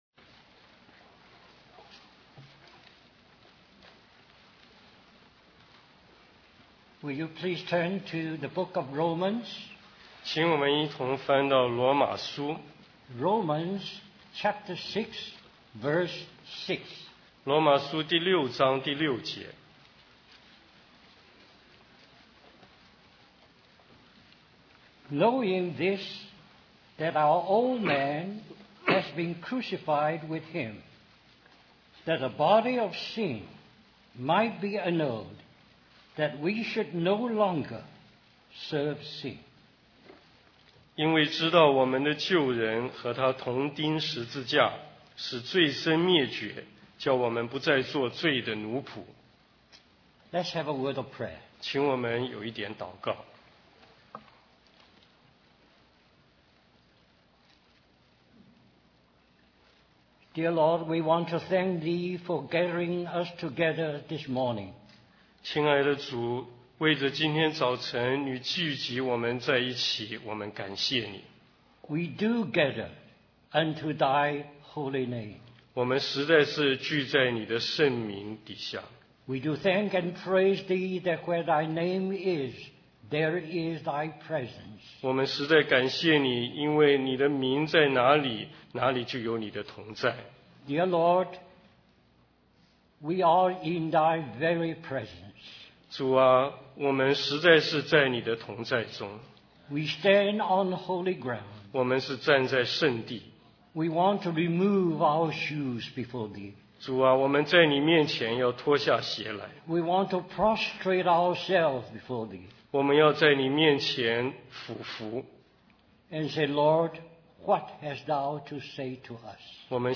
West Coast Christian Conference